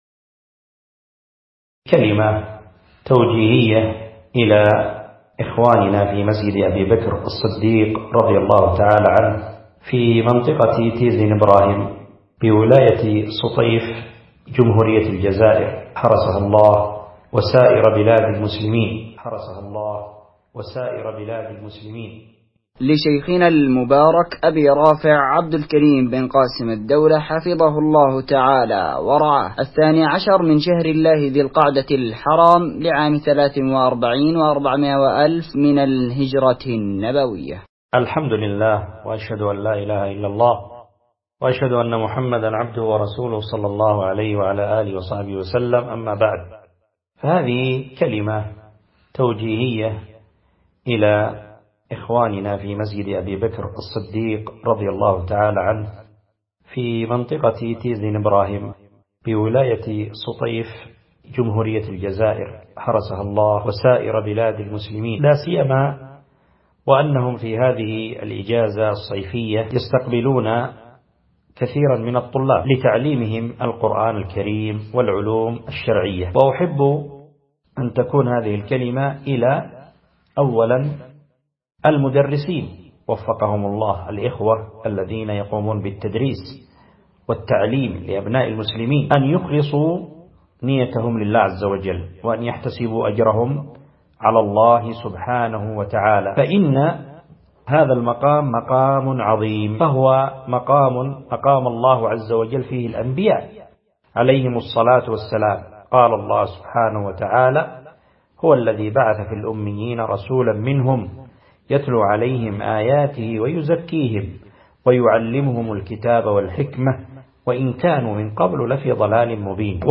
كلمة توجيهية إلى الدورة العلمية منطقة تيزينبراهم